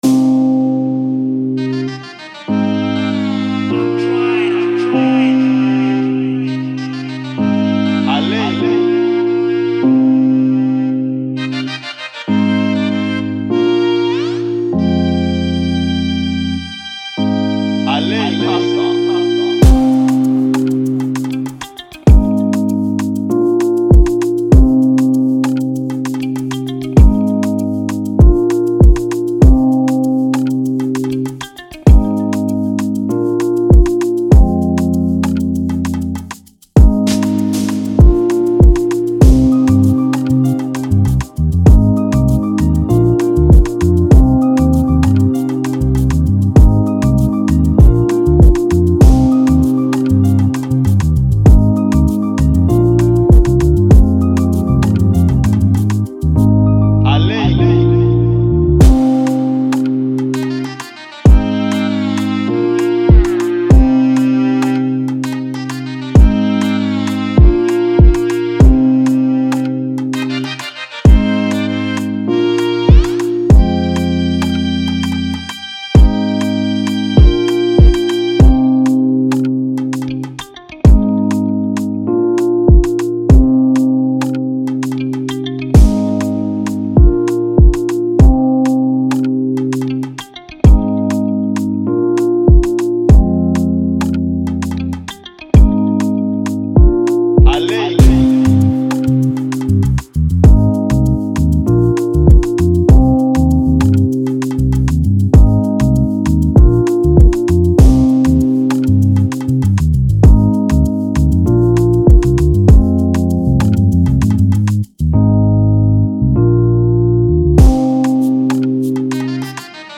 remake beat instrumental